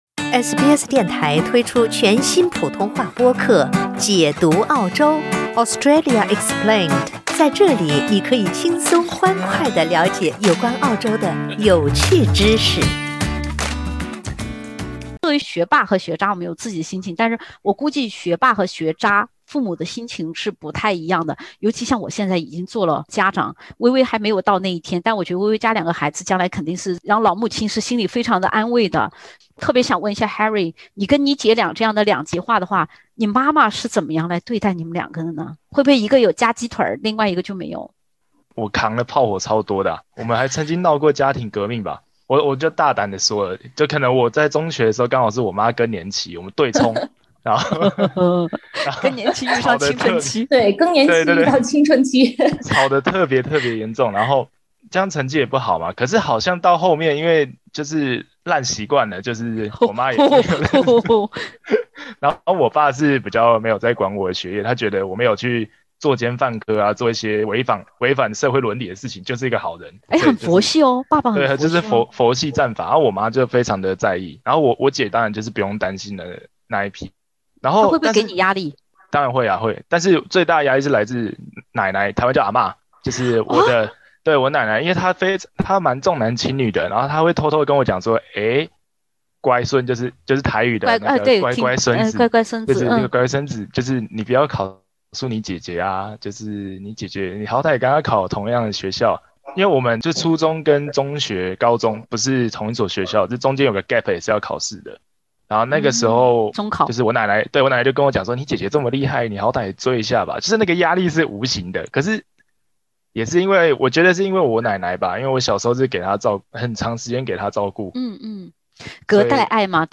（点击封面图片，收听风趣对话）